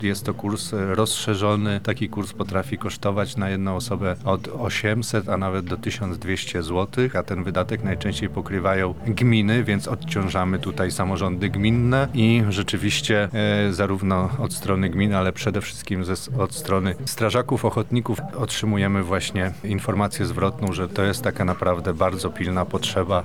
Marek Wojciechowski-podkreśla Marek Wojciechowski, Wicemarszałek Województwa Lubelskiego.